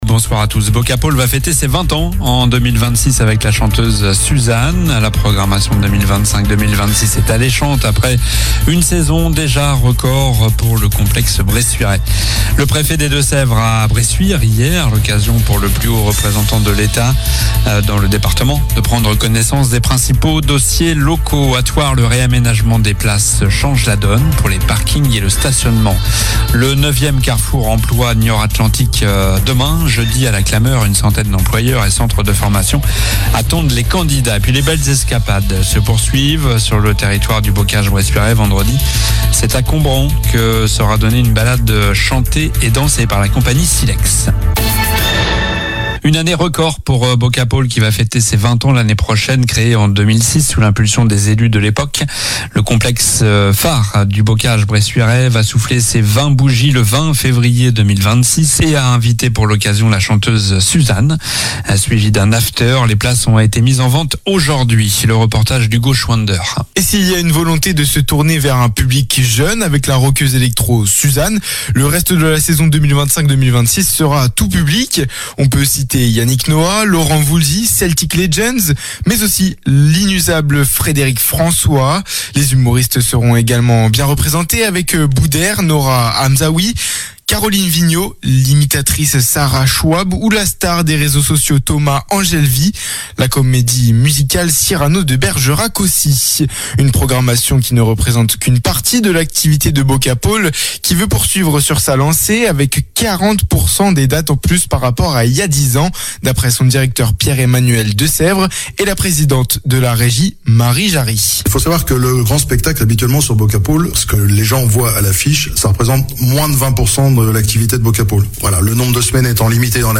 Journnal du mercredi 4 juin (soir)
infos locales